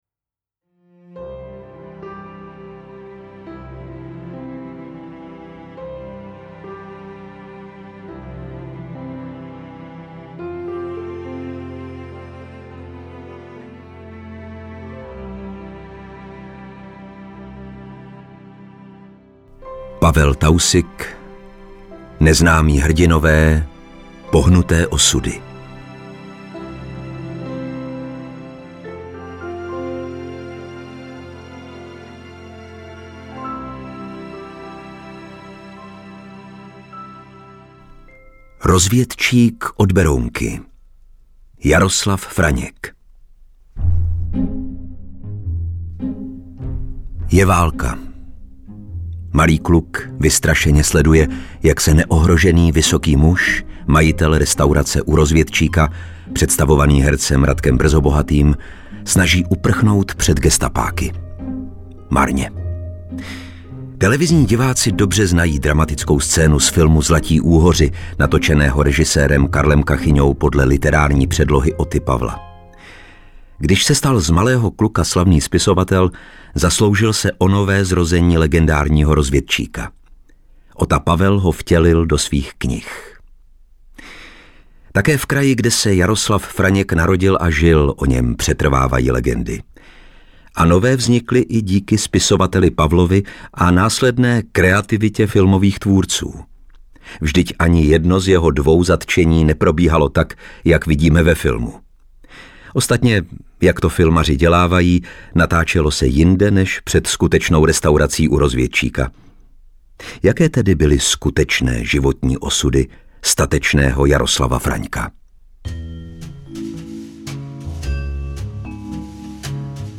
Interpret:  Lukáš Hlavica
AudioKniha ke stažení, 7 x mp3, délka 1 hod. 55 min., velikost 157,7 MB, česky